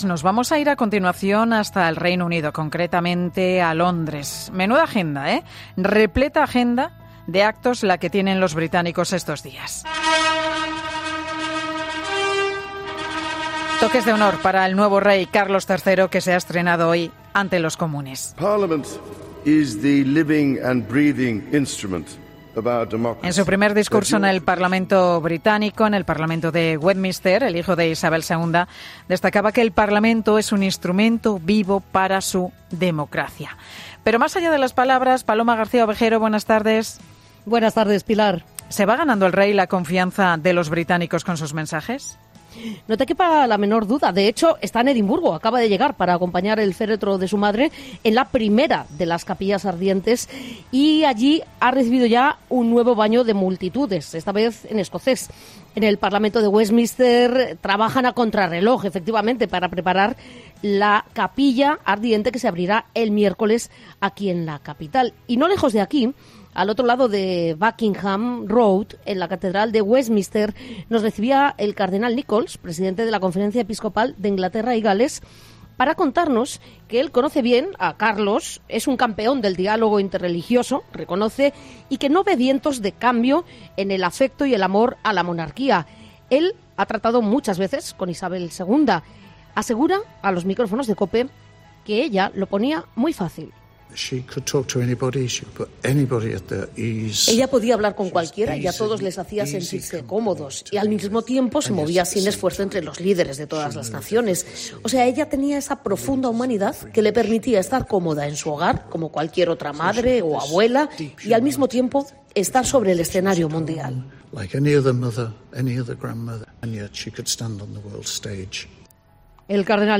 Paloma García Ovejero, enviada especial a Londres: "Carlos III se está ganando la confianza de los británicos"